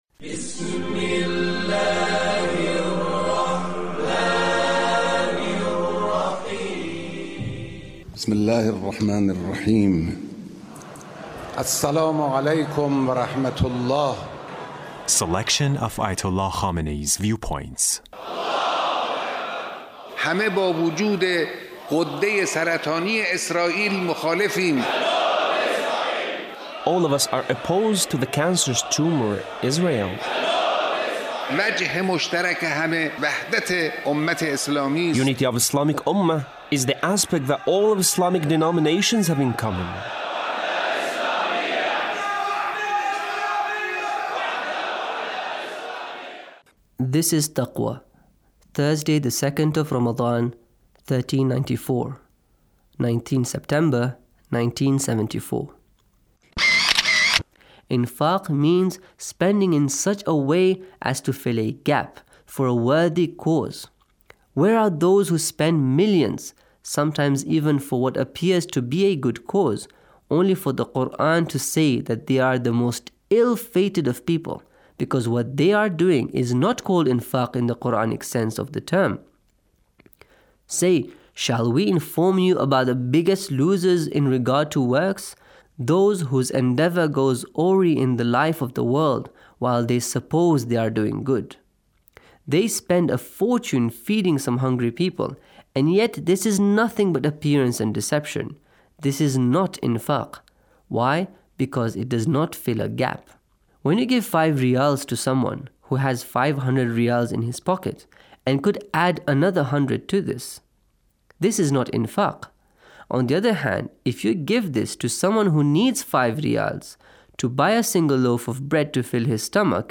Leader's Speech on Taqwa